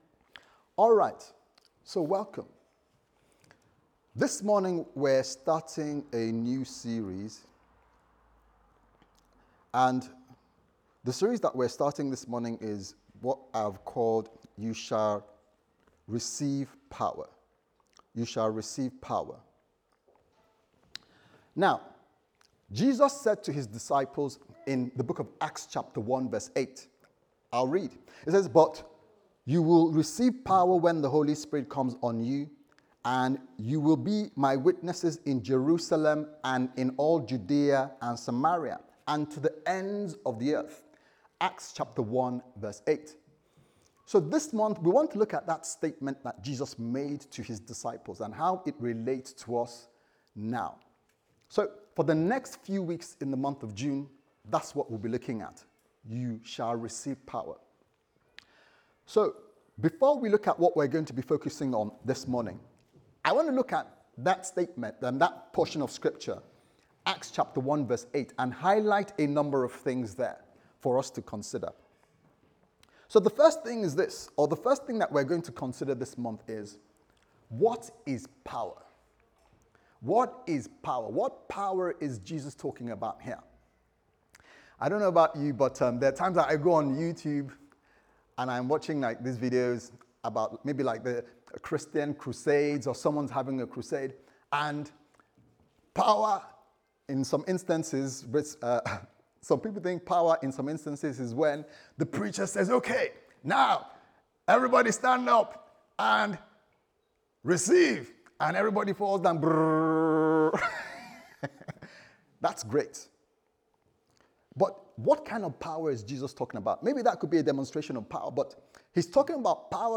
Sunday Service Sermon « How Do I Find And Follow God’s Plans For My Life